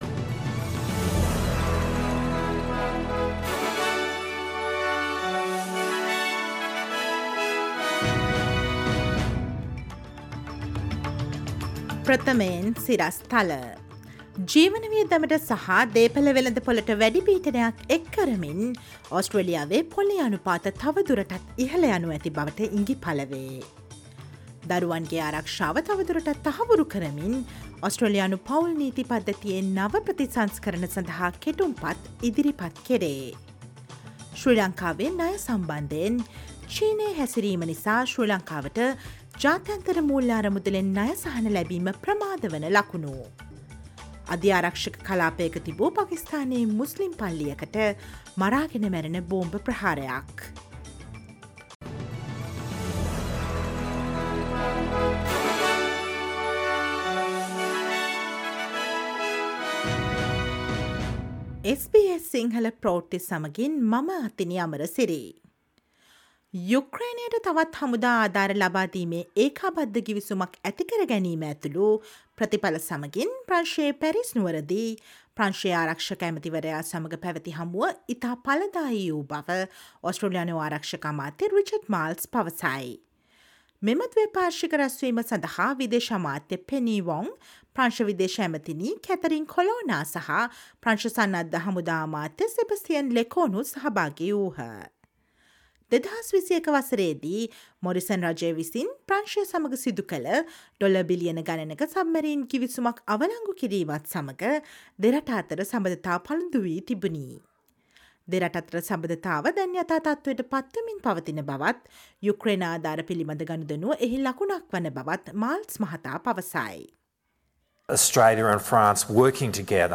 Listen to the latest news from Australia, fro Si Lanka ,across the globe, and the latest sports news on SBS Sinhala radio on Monday, 31 January 2023.